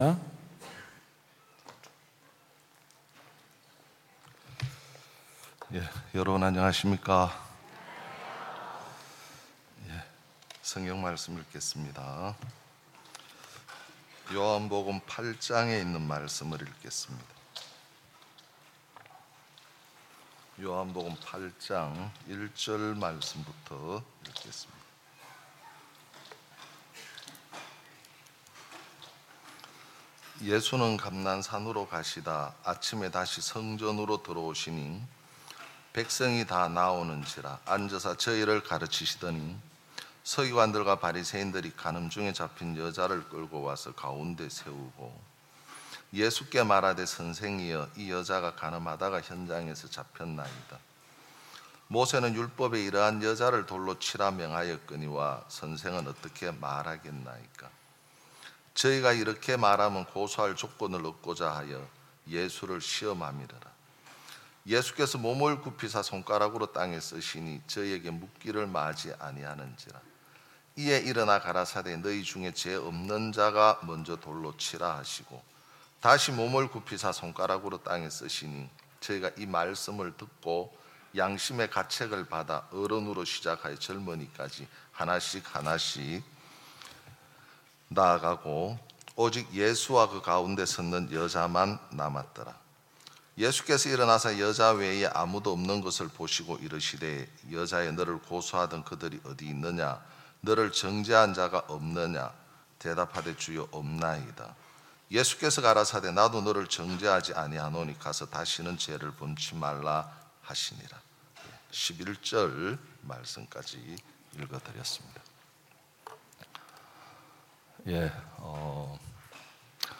2022 여름 캠프(강릉) 3차 #7 가장 악한 자에게 임하는 하나님의 은혜 좋아요 즐겨찾기 프로그램 소개 프로그램 응원 공유 다운로드 태그 이 콘텐츠를 보시고 떠오르는 단어는 무엇입니까?